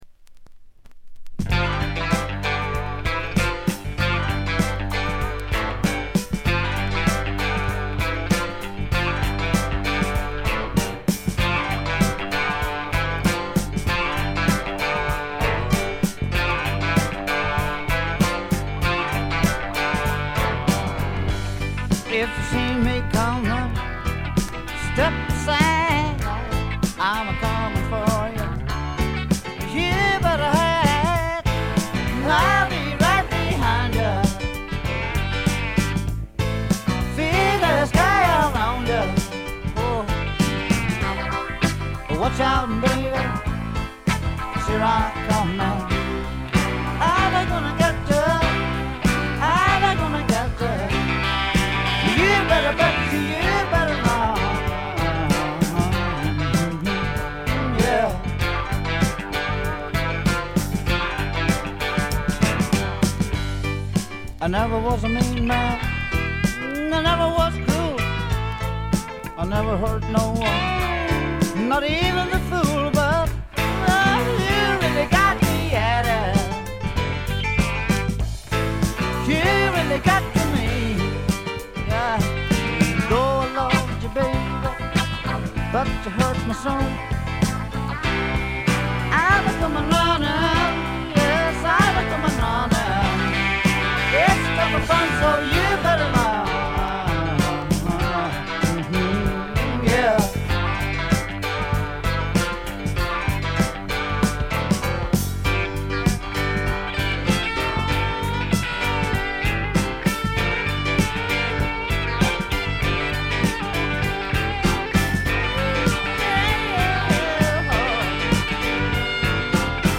微細なノイズ感のみ。
ルーズでちょこっと調子っぱずれなあの愛すべきヴォーカルがまたよくて、本作の雰囲気を盛り上げています。
試聴曲は現品からの取り込み音源です。